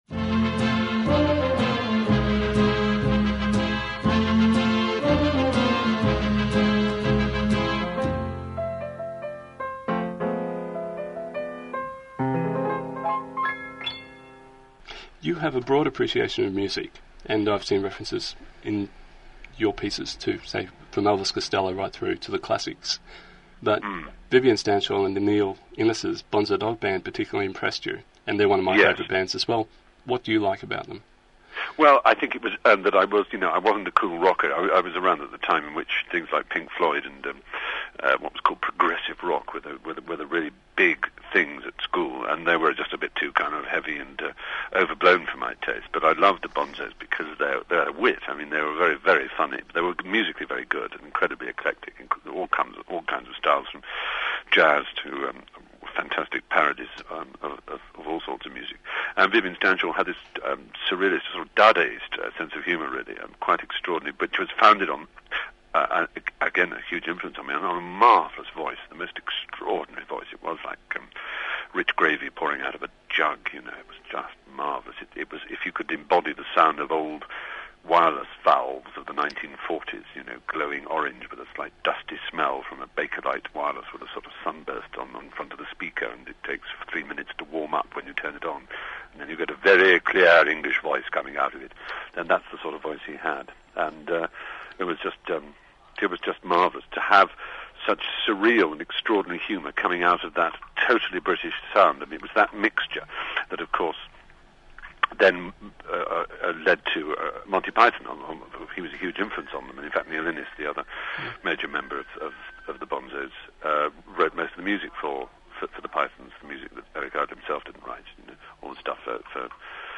Stephen Fry interview audio and transcript 7th Feb 2001 for Queer Radio on 4ZZZ Brisbane
Hear this section as a 1.4mb, 48kps mono mp3